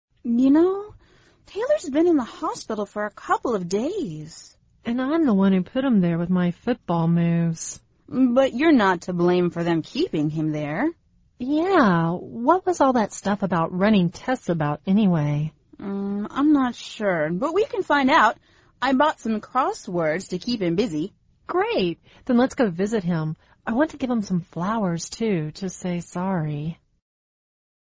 美语会话实录第242期(MP3+文本):You're not to blame!